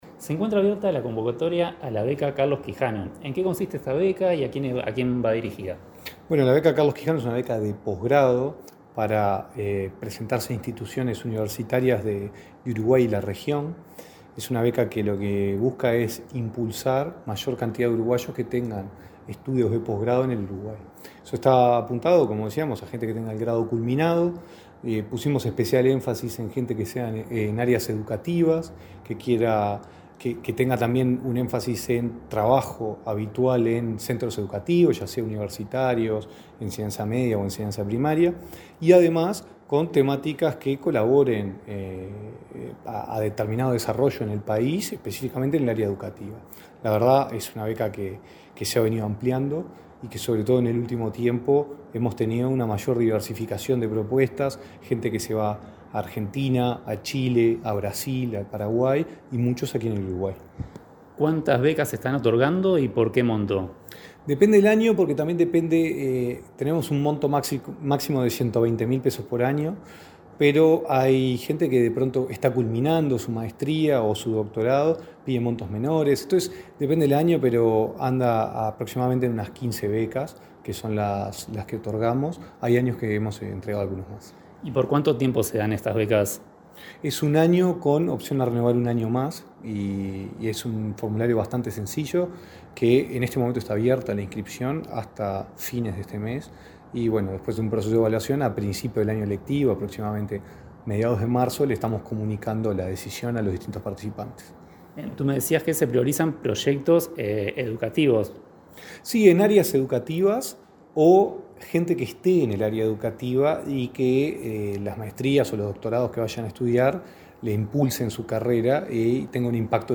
Entrevista al director de Educación, Gonzalo Baroni